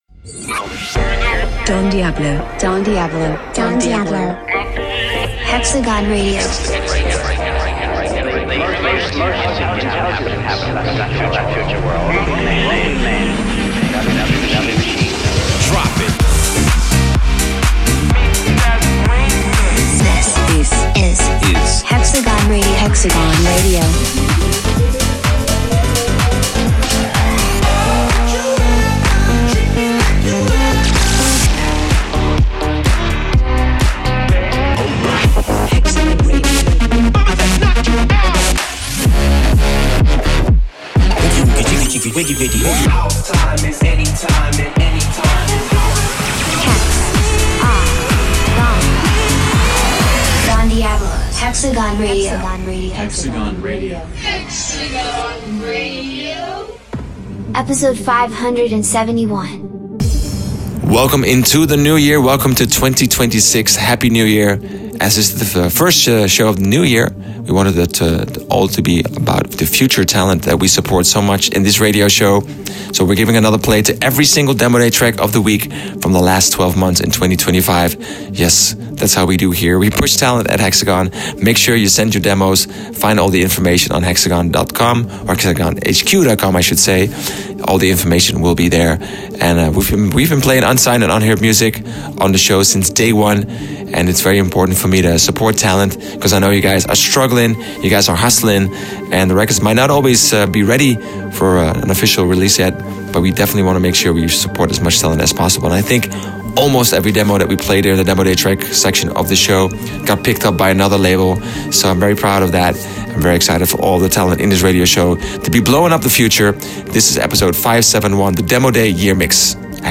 music DJ Mix in MP3 format
Genre: Bass House